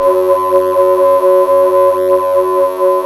SYN TECHNO09.wav